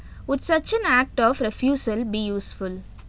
Phonetically Rich Audio Visual (PRAV) corpus
a2302_F2.wav